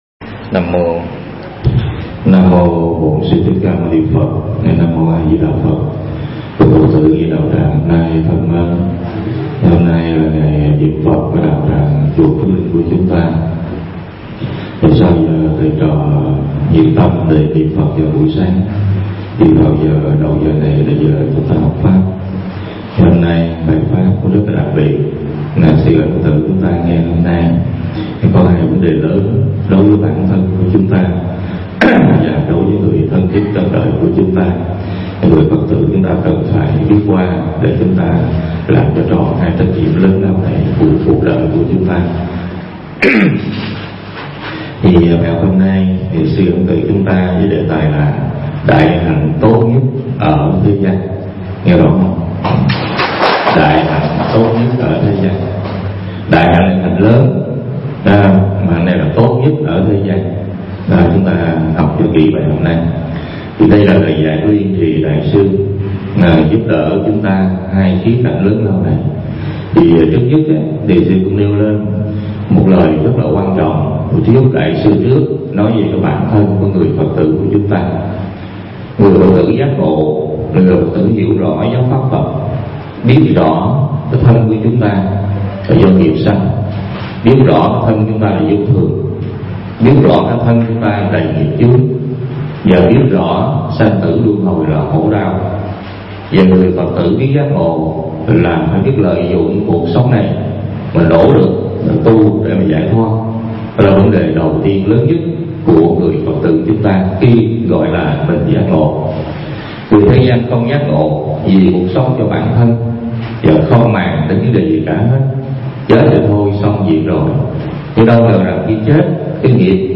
Nghe mp3 Pháp Âm Đại Hạnh Tốt Nhất Ở Thế Gian